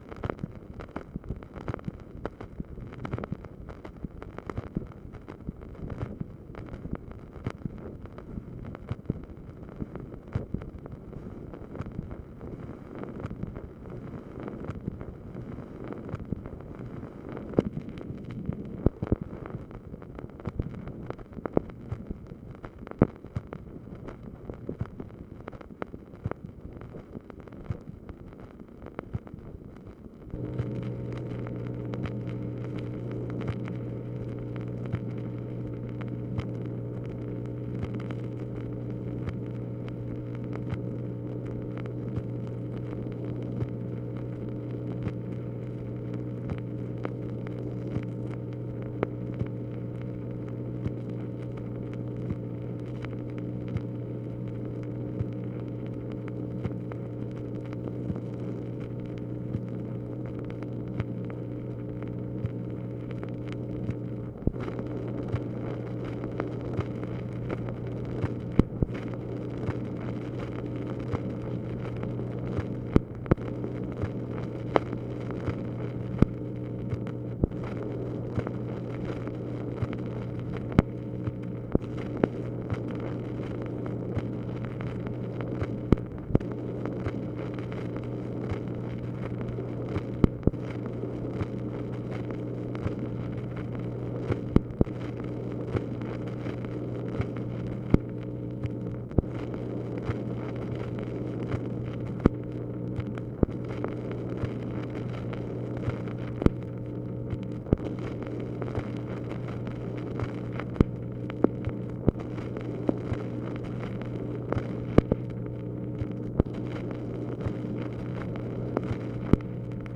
MACHINE NOISE, February 15, 1965
Secret White House Tapes | Lyndon B. Johnson Presidency